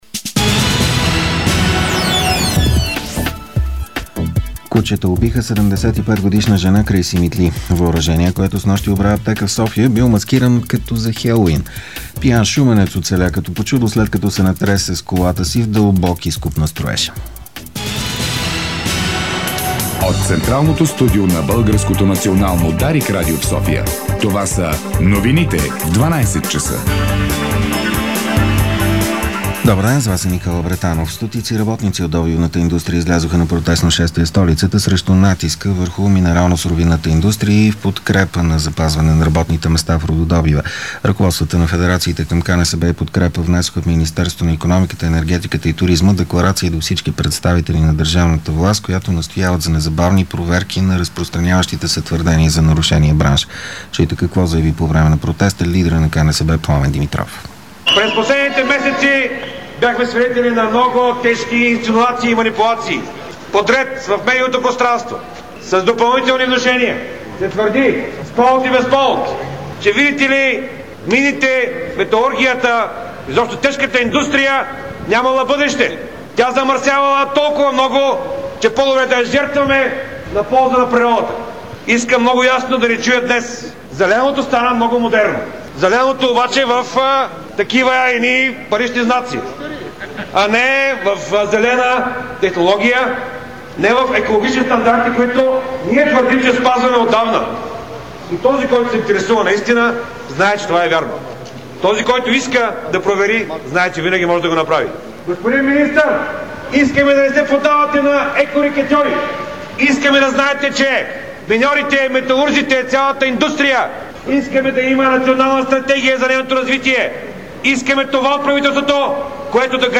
Обедна информационна емисия - 01.11.2012